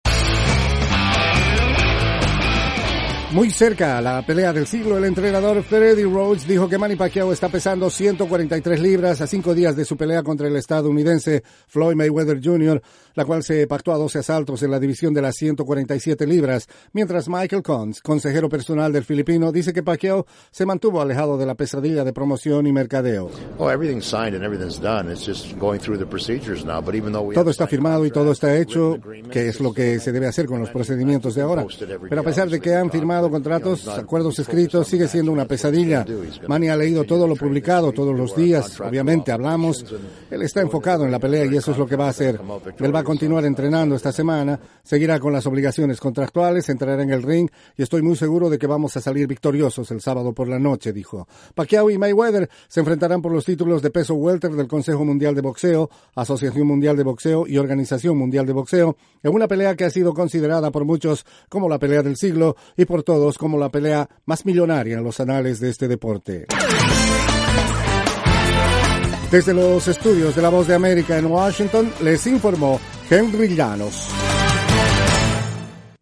Manny Pacquiao ya está en Las Vegas para encarar los últimos días de su preparación con miras a la denominada Pelea del Siglo, frente a Floyd Mayweather jr. Informa